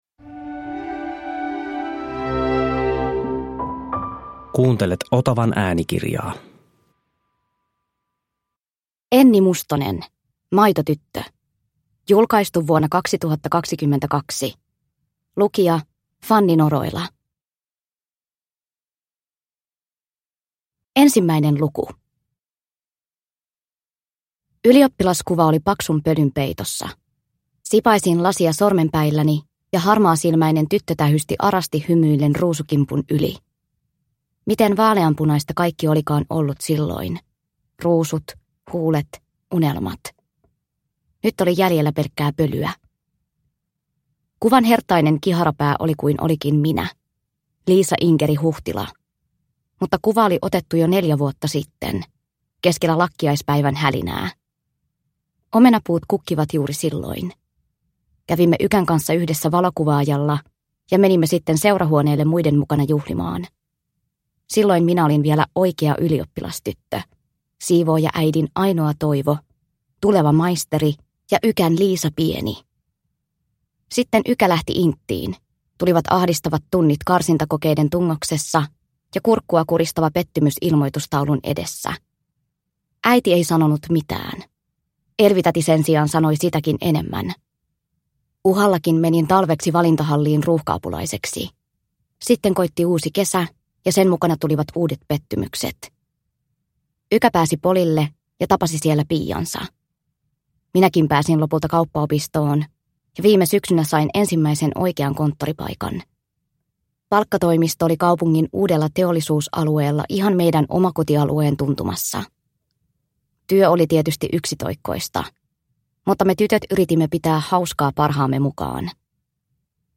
Maitotyttö – Ljudbok – Laddas ner